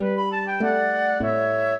flute-harp
minuet14-7.wav